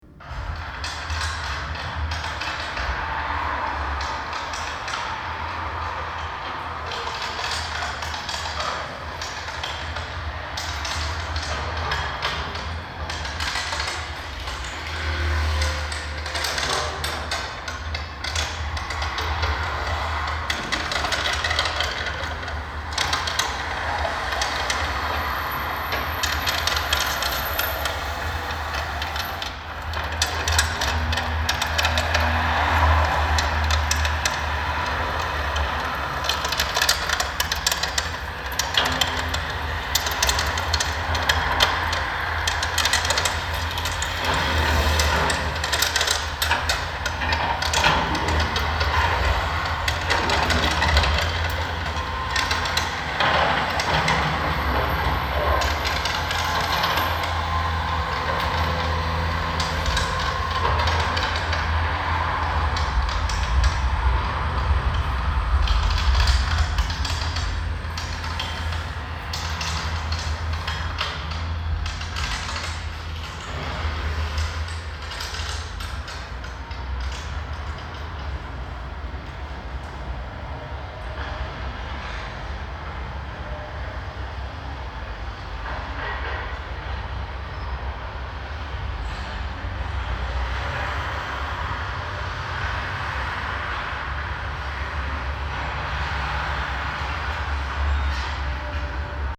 Which was necessary to assure an as smooth as possible movement of the little wagons carrying the speakers, so that none of them will get stuck during the four-part linear choreography of the eight minute dynamic city sound scape that comprises the current, first version, of City Chase.
Here’s is a short sound fragment, that I recorded during one of the City Chase‘s test rides early that Saturday afternoon:
But most city sounds are highly complex, and in many cases, indeed, the (multiple) sound sources in the recording are themselves also moving, thus severely testing the limits of our ability to perceive and distinguish the different kinds of movement of the sounds that are involved.